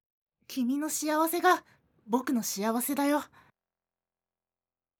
女性
ボイス